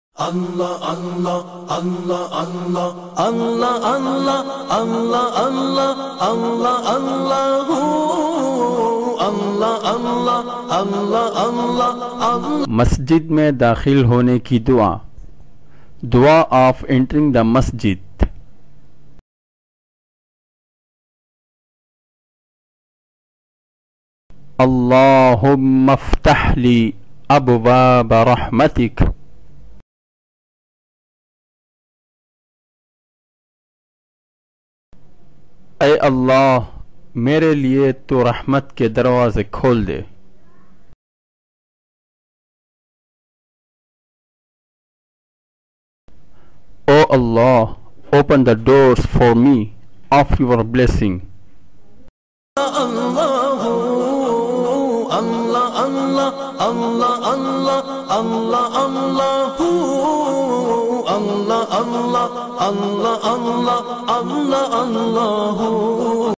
Dua When Entering the Mosque, listen online mp3 or download audio arabic recitation free in best audio quality.
Dua-For-entering-in-mosque.mp3